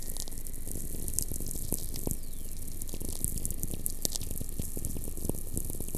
Heidelberg, Germany